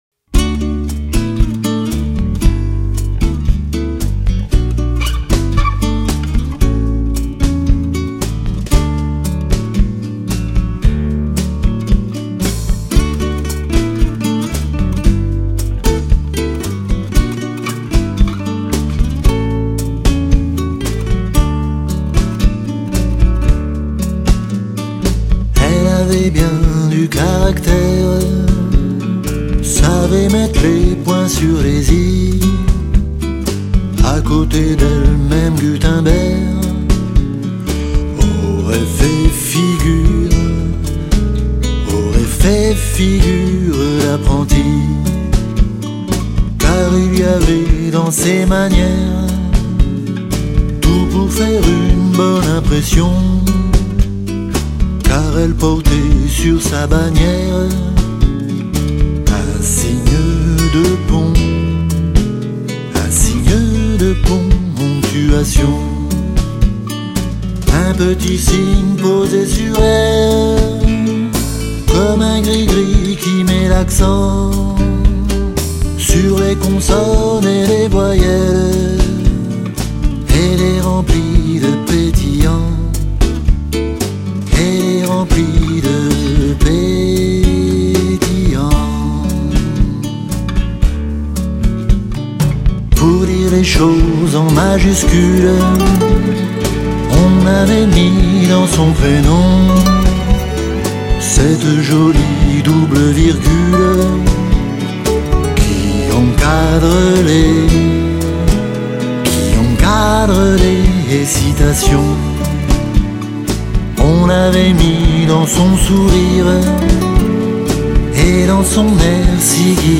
une ambiance plus mélancolique, plus blues, plus bossa